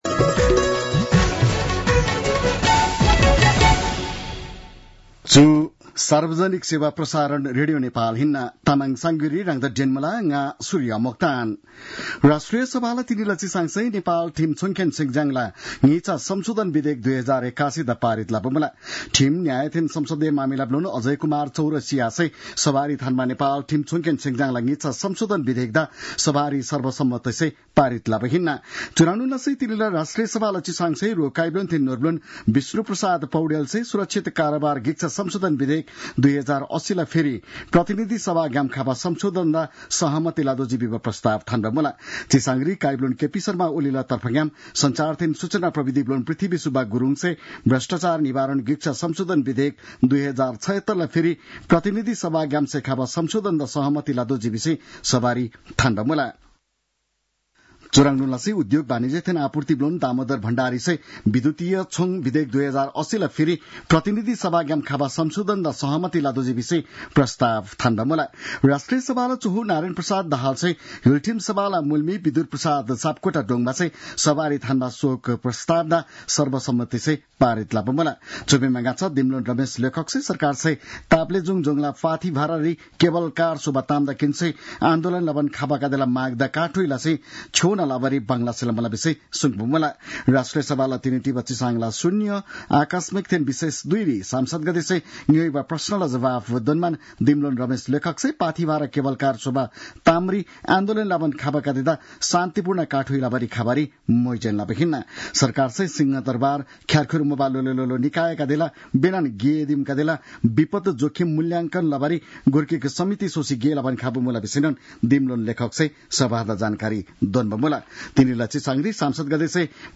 An online outlet of Nepal's national radio broadcaster
तामाङ भाषाको समाचार : १९ फागुन , २०८१